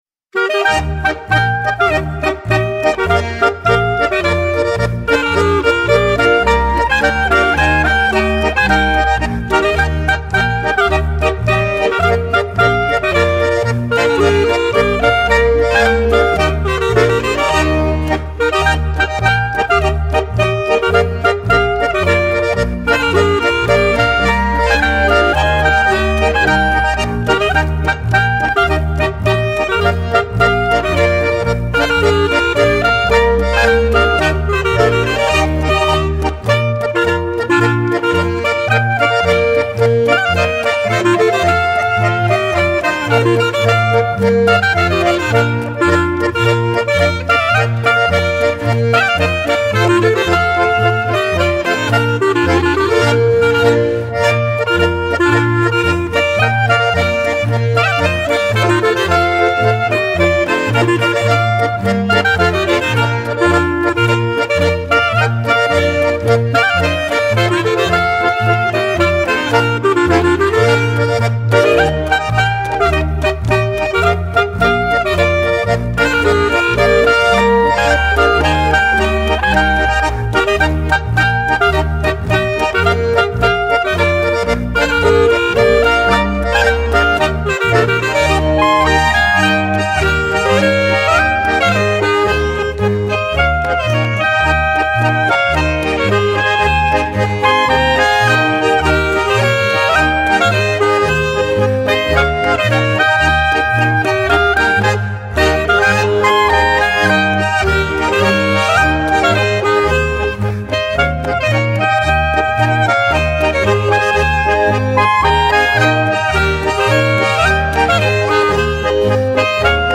Schottisch.